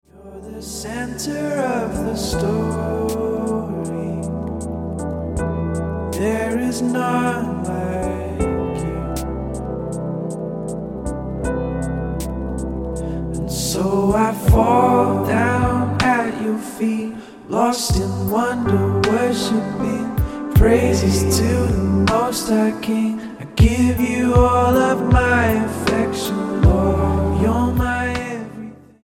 STYLE: Ambient/Meditational
is in a suitably sombre mood